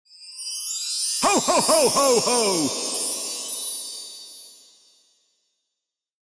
Cheerful-santa-ho-ho-ho-with-chimes-sound-effect.mp3